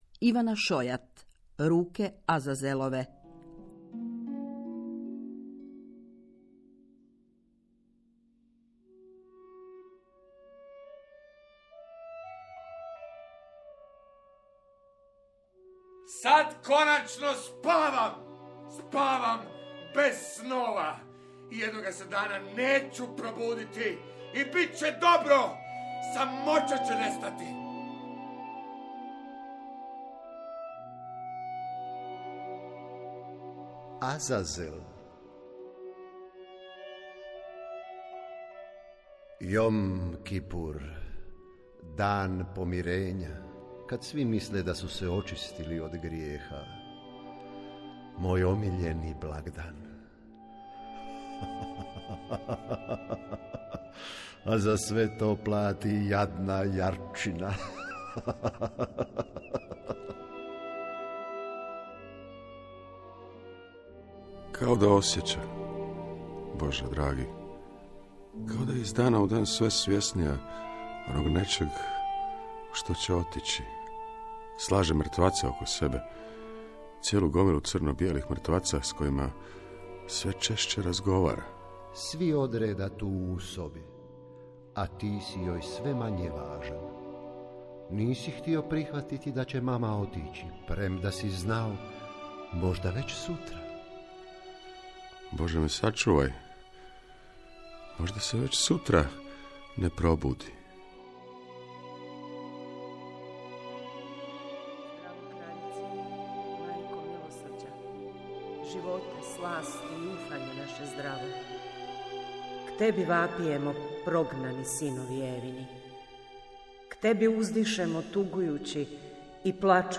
Radio drama – Podcast
Glumačke ekipe okupljene u ovim zahtjevnim radiofonskim projektima najzvučnija su imena hrvatskog glumišta koja na radiju nalaze svoje umjetničko utočište.